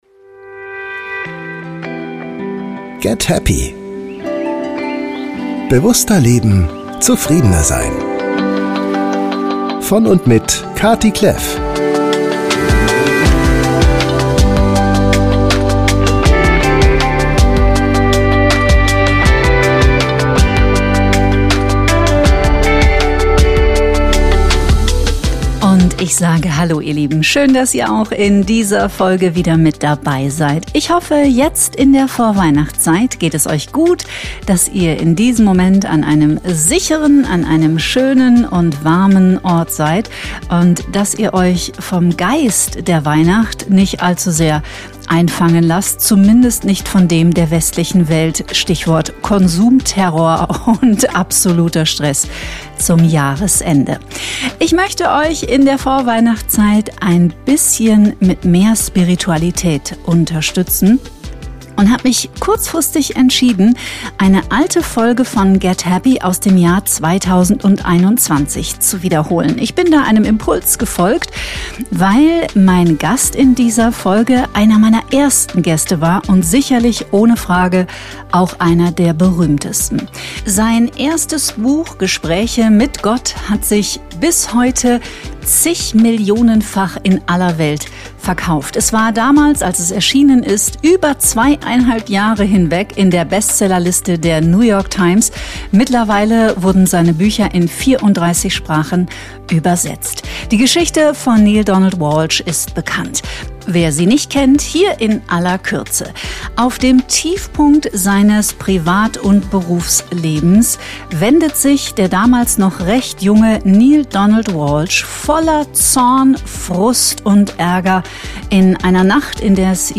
Zum Jahresausklang 2025 kommt hier nochmal mein Gespräch mit Neale aus dem Dezember 2021.